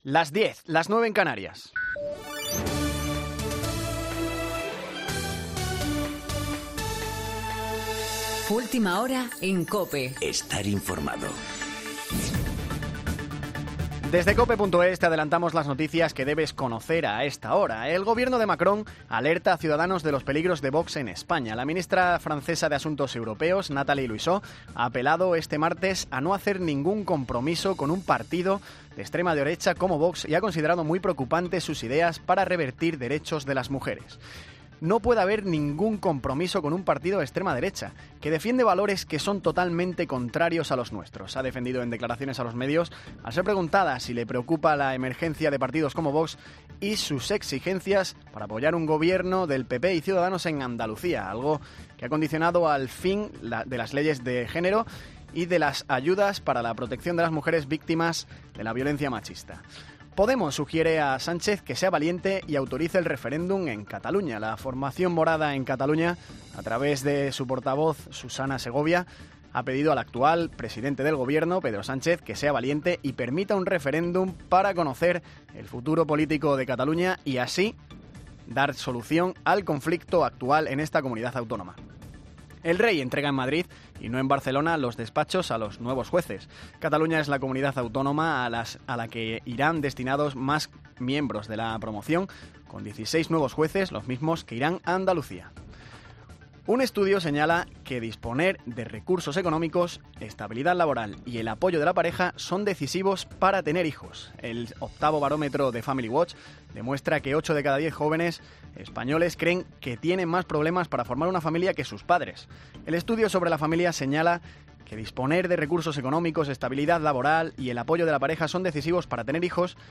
Boletín